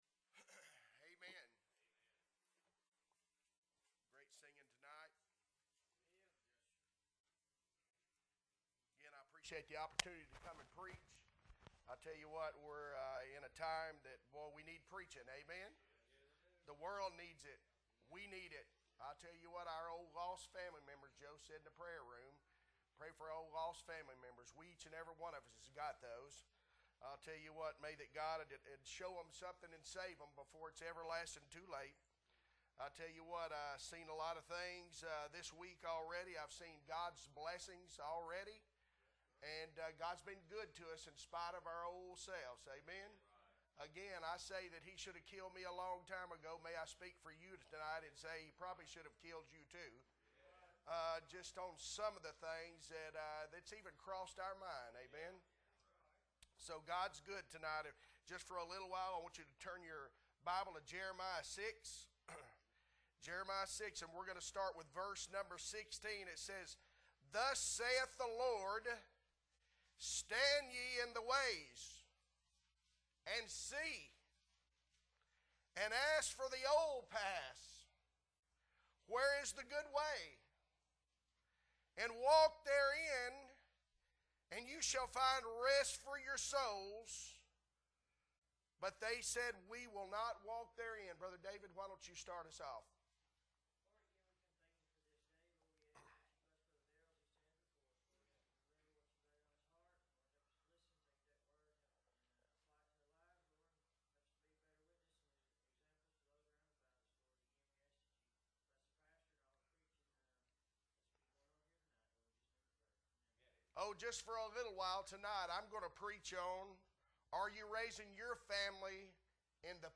October 2, 2022 Sunday Evening Service - Appleby Baptist Church